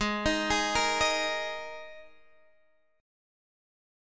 G#sus2 chord